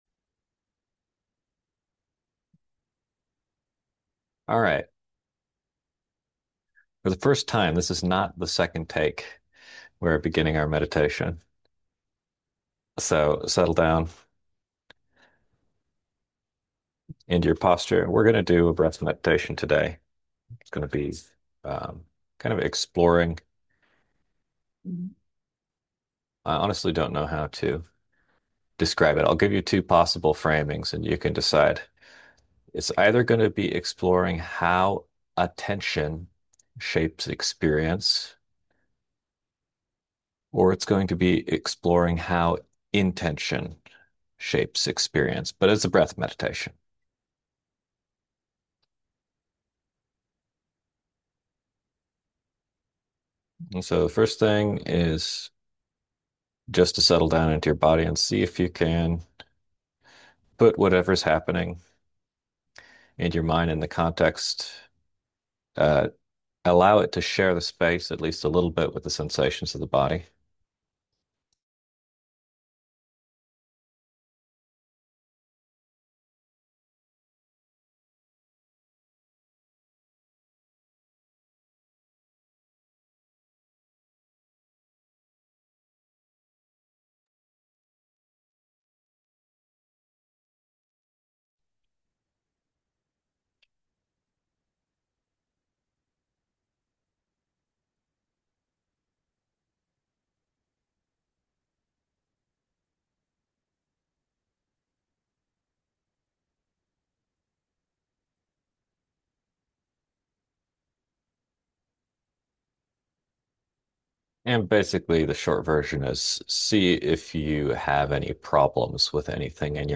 The first recording attempt failed so this is an awkward restart.
Podcast (guided-meditations): Play in new window | Download